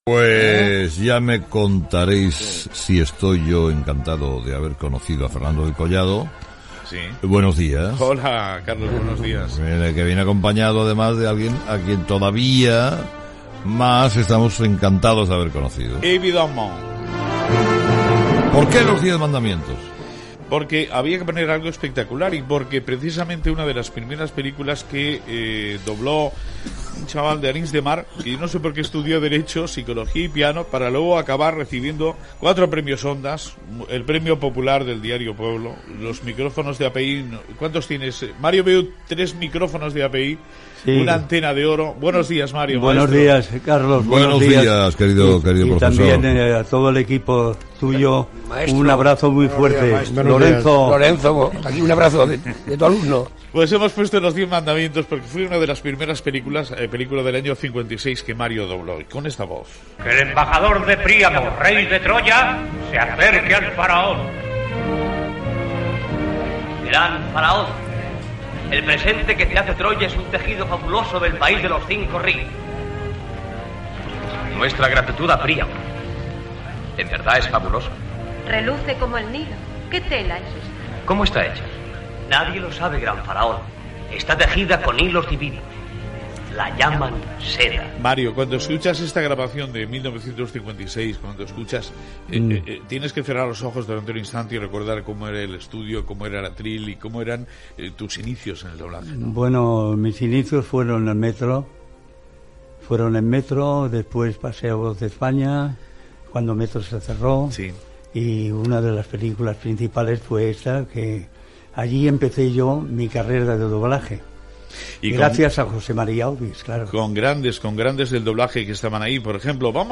Entrevista
Info-entreteniment